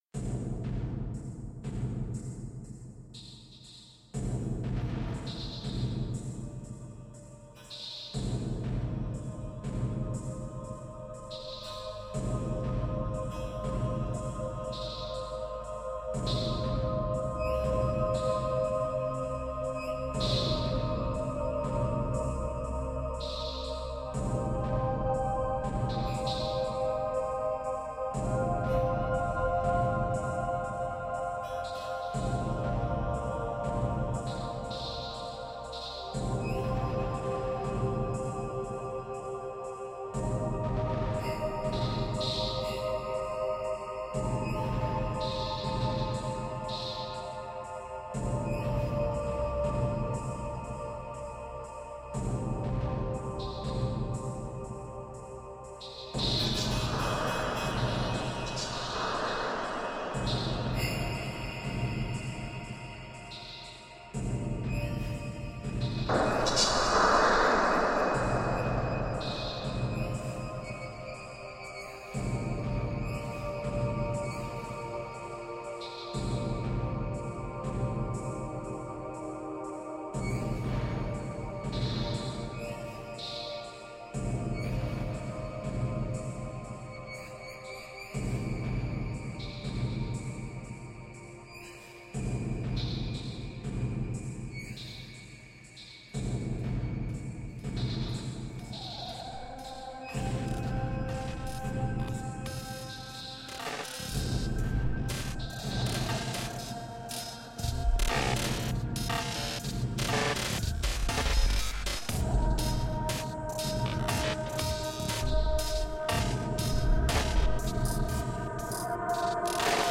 Haunting, ethereal pop ambient.
Tagged as: Ambient, Rock, Pop, Electro Rock, Ethereal